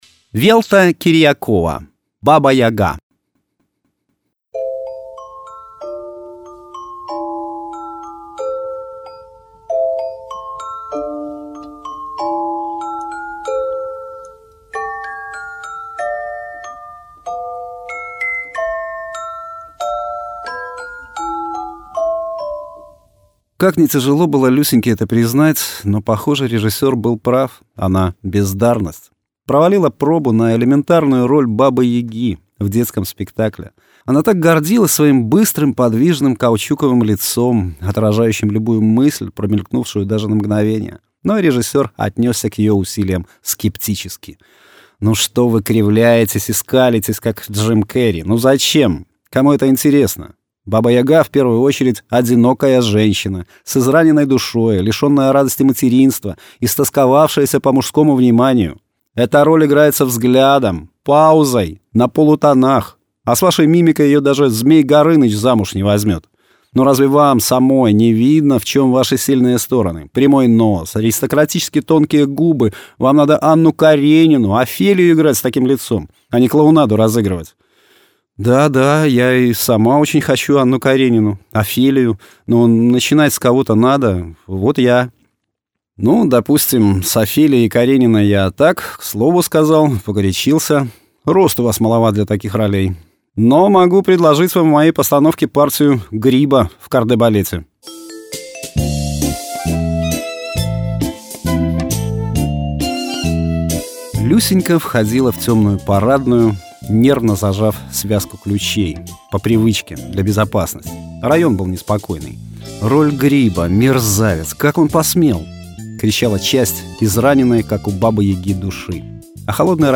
Аудиокниги
Аудио-Рассказы
Жанр: Современная короткая проза
Качество: mp3, 256 kbps, 44100 kHz, Stereo